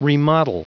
Prononciation du mot remodel en anglais (fichier audio)
Prononciation du mot : remodel